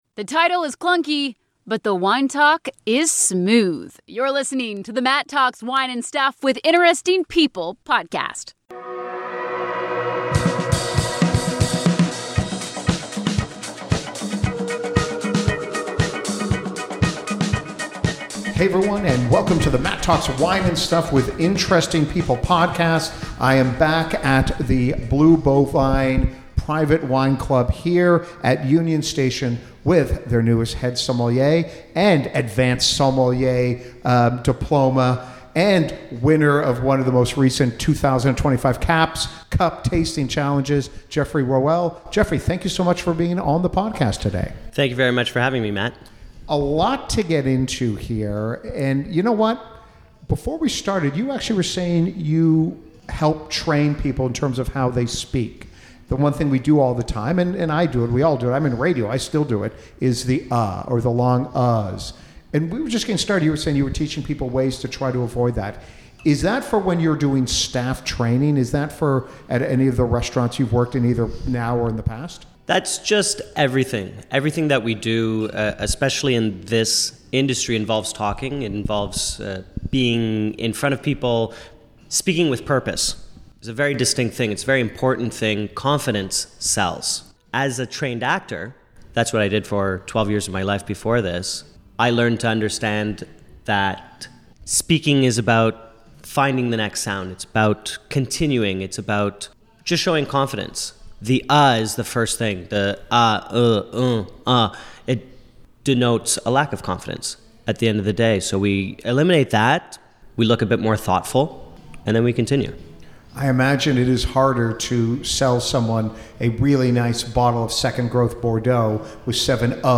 An engaging conversation on all things wine competition, training to be a Master of Wine and what goes into proper wine service.